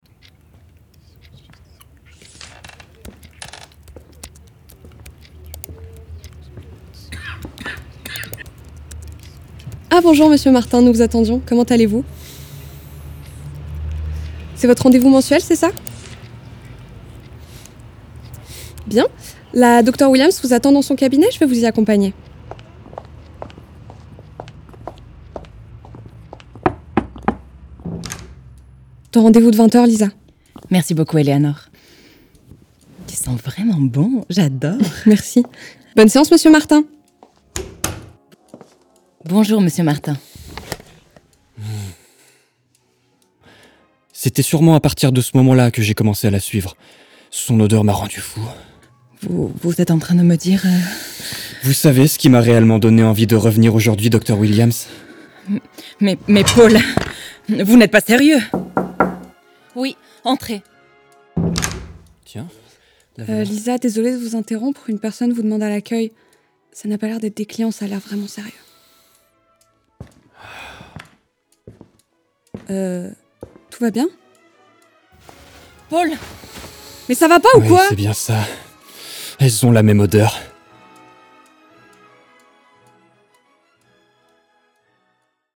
Comédienne
Voix off